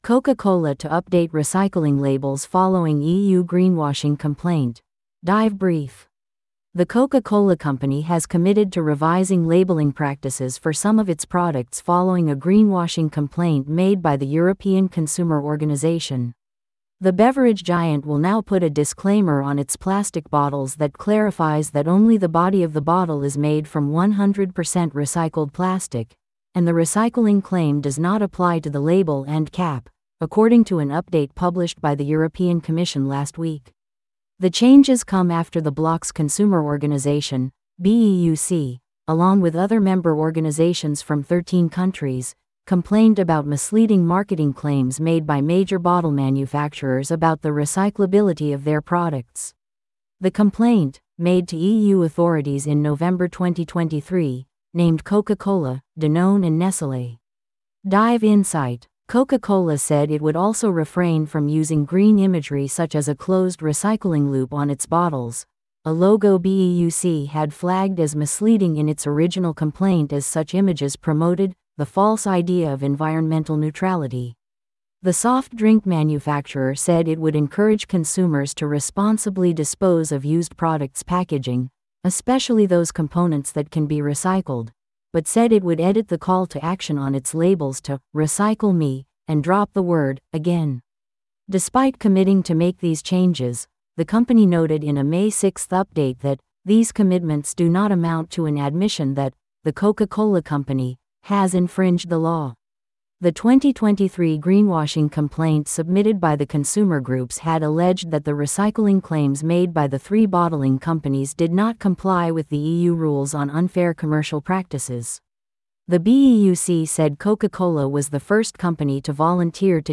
This audio is auto-generated. Please let us know if you have feedback.